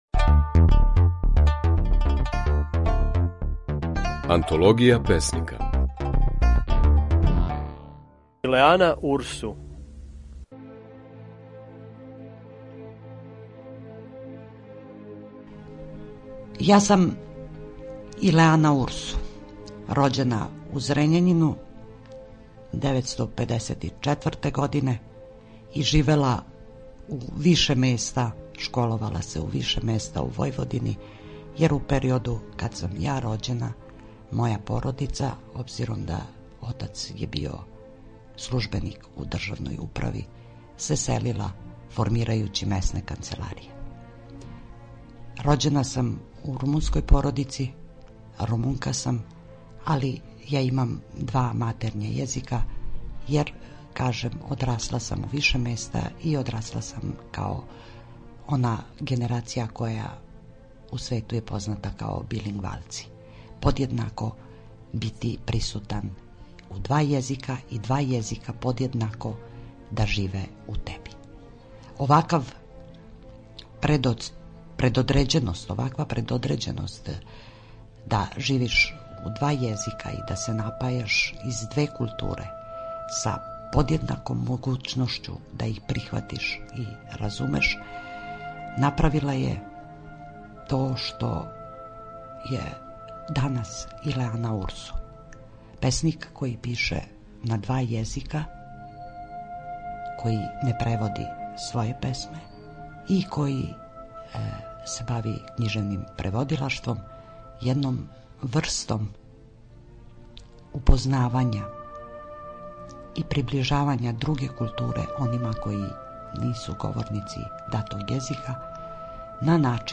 Можете чути како је своје стихове говорила